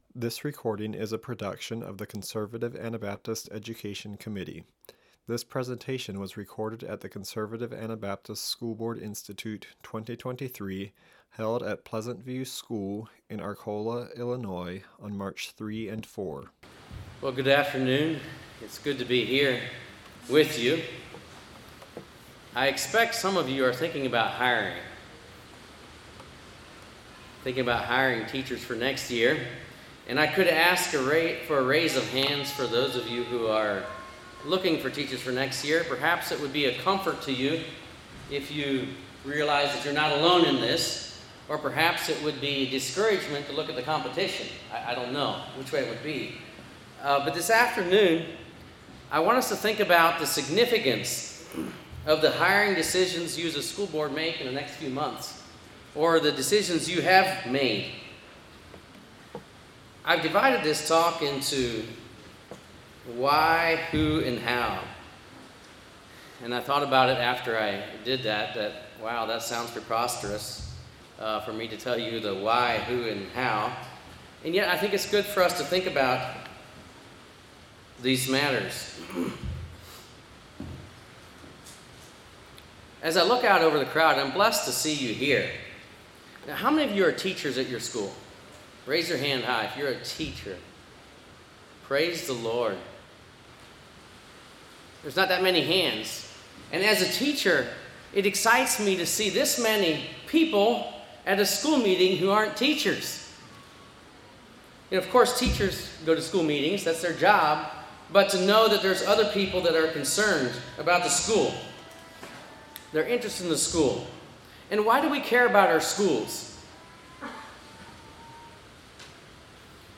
Home » Lectures » Hiring Your Future Church